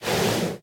breathe2.ogg